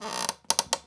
chair_frame_metal_creak_squeak_13.wav